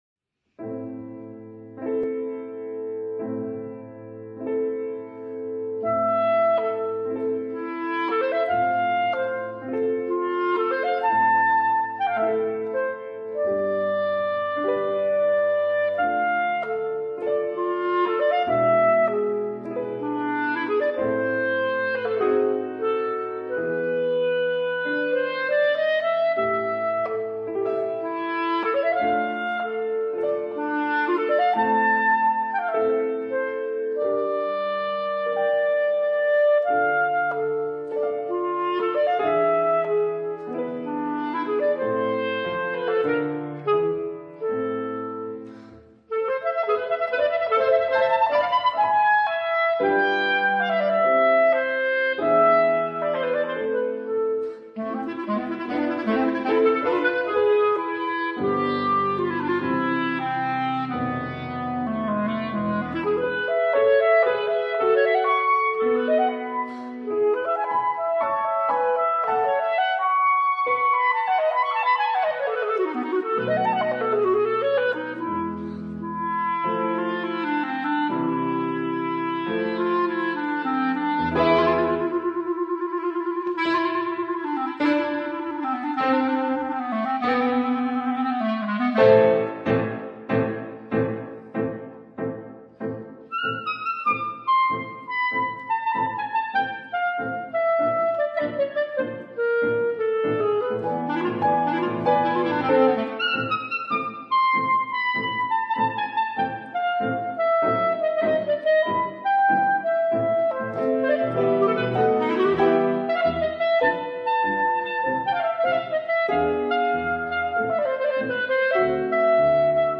pour clarinette en La et piano